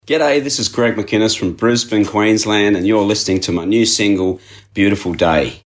Radio Intro 2